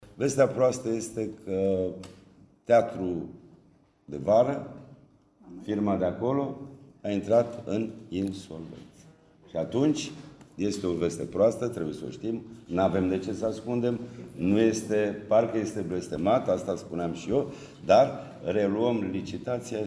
Președintele Consiliului Județean Constanța, Florin Mitroi, a anunțat, astăzi, că lucrările sunt, deocamdată, oprite.